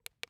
ui-click.wav